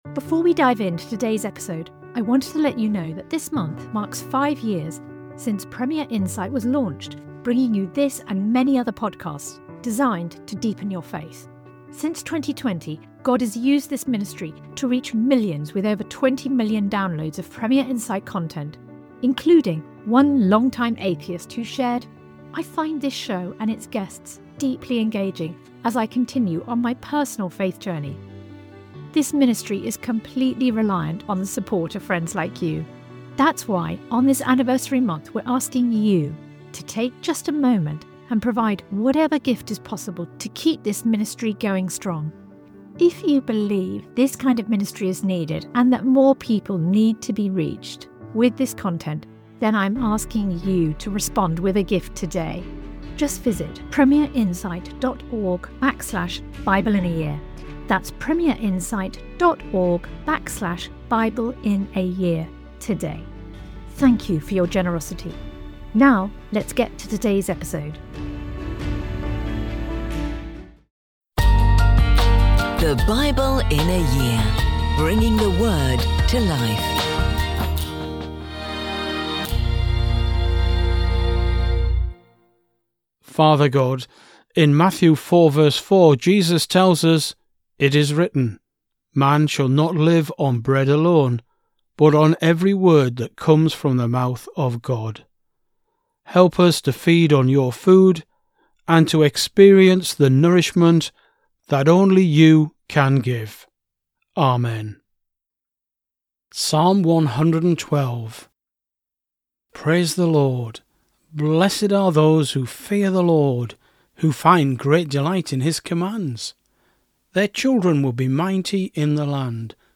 Every day throughout the year we'll be bringing you an audio scripture reading from the Old and New Testament.